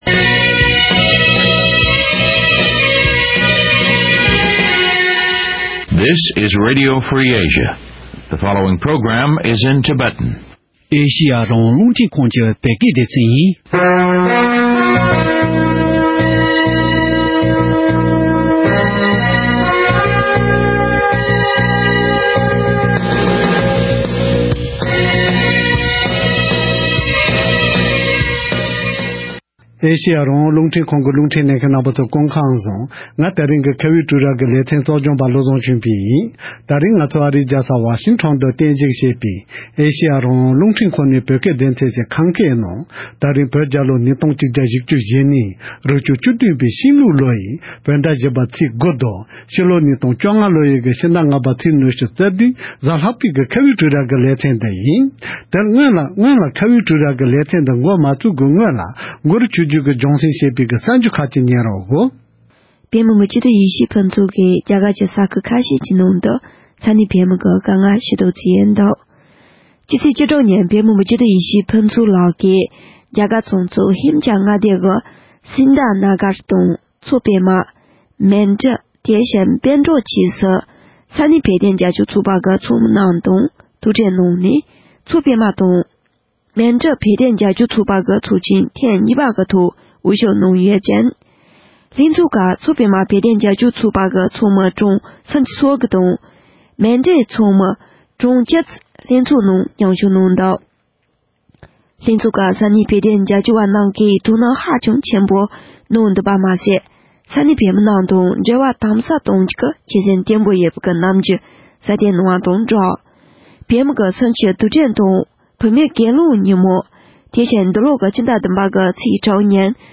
དེའི་སྐོར་འབྲེལ་ཡོད་ཁག་ཅིག་དང་ལྷན་དུ་གླེང་མོལ་ཞུས་པ་ཞིག་གསན་རོགས་གནང་།།